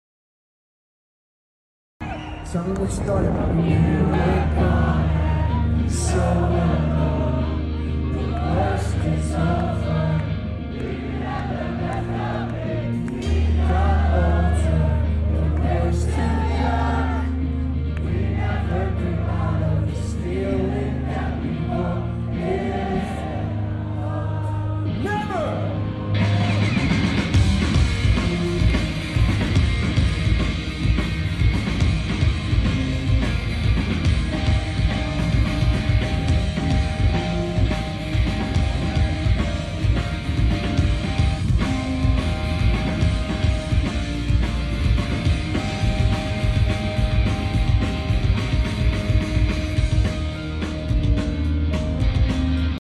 Slam Dunk, Leeds.
Festivals are always so good